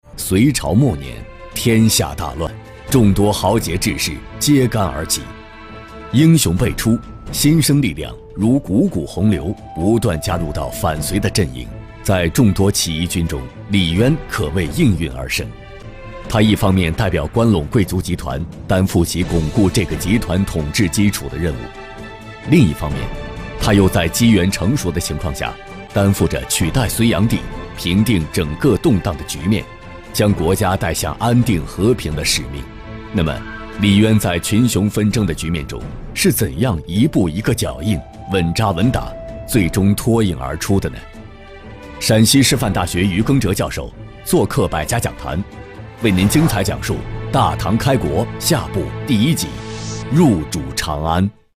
栏目配音